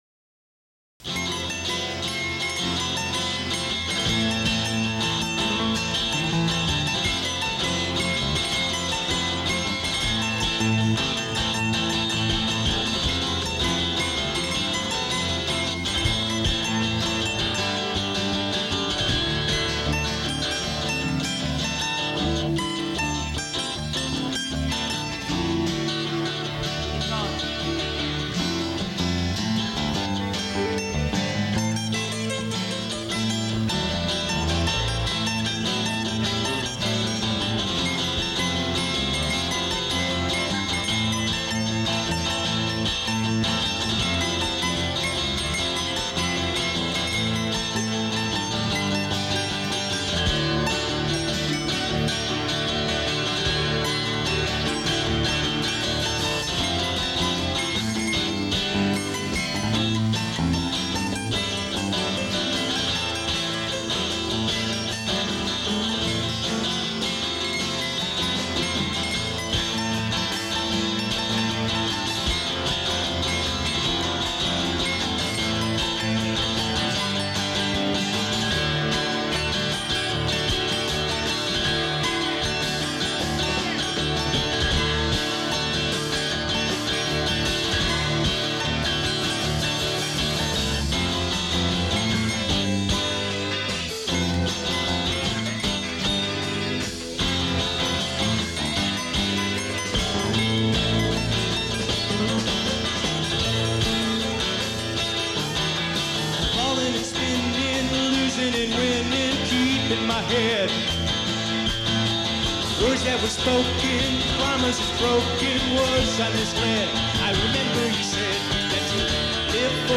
Category Rock/Pop
Studio/Live Live
mandolin, guitar and vocals
bass
drums and percussion